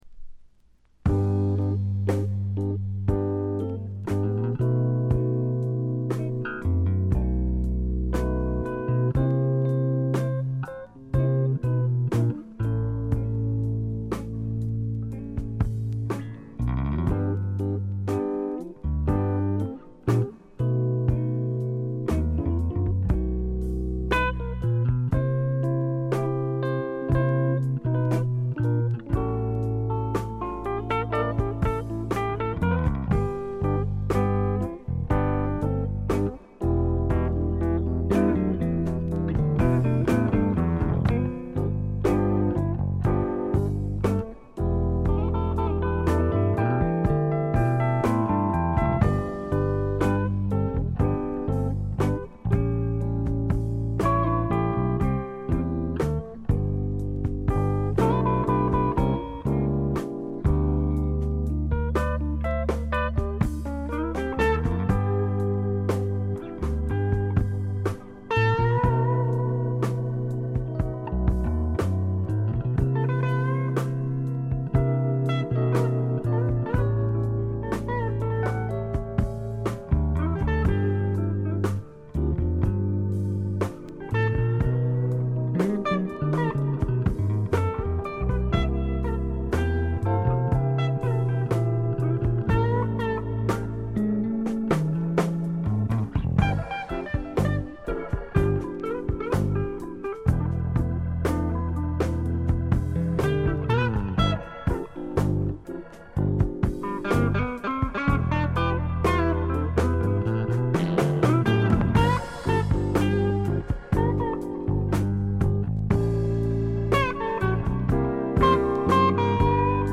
ファンキーでジャズっぽい面もあるサウンドが心地よくくせになります。
試聴曲は現品からの取り込み音源です。